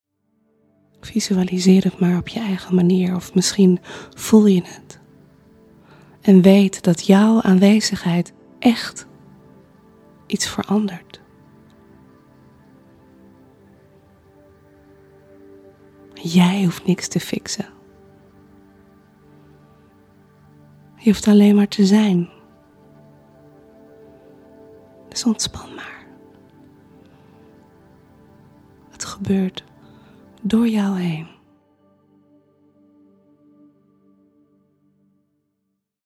Meditatie waarin je het Licht in jezelf bekrachtigt en door jou heen laat stromen, deze wereld in.